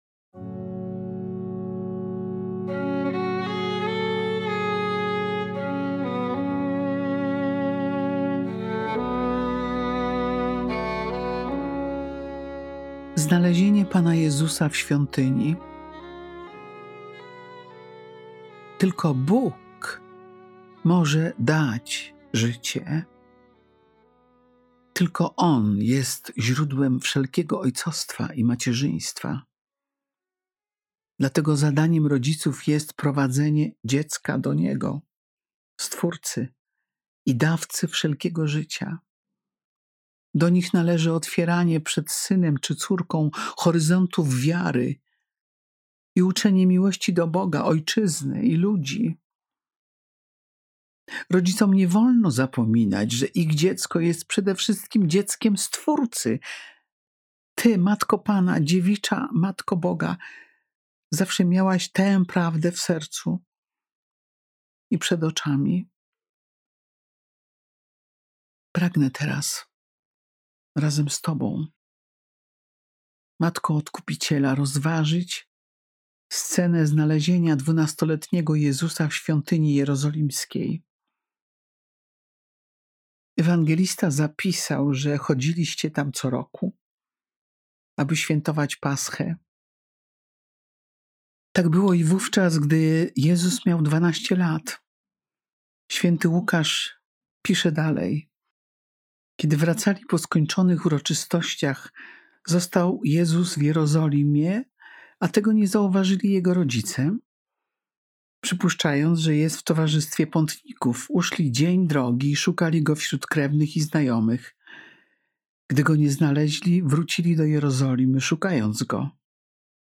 MEDYTACJA PIERWSZA SOBOTA MIESIĄCA